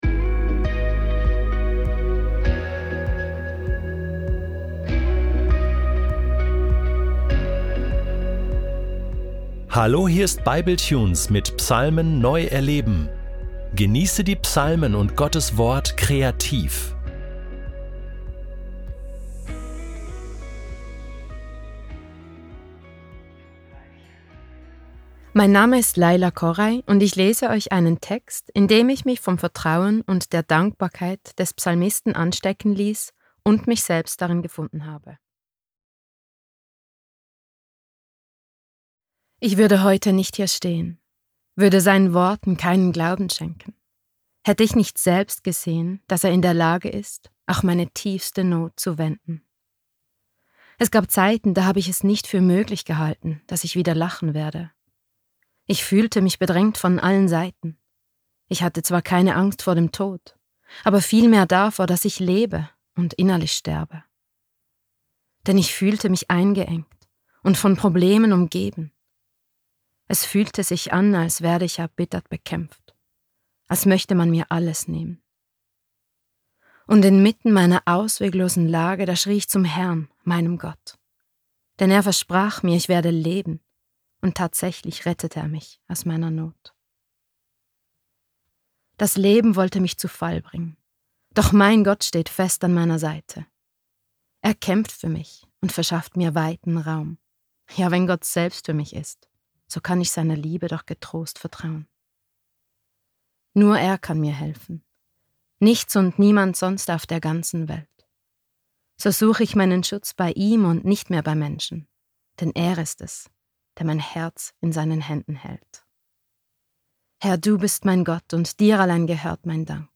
Der Psalm wird anschließend in einem Lied vertont, das Gottes Hilfe, Schutz und beständige Gnade besingt – auch angesichts von Feinden und Bedrohungen. Im Zentrum steht das Bekenntnis: Der Herr ist Stärke, Retter und Grund zur Freude, weshalb Dank, Lob und das „Hosanna“ aus vollem Herzen erklingen.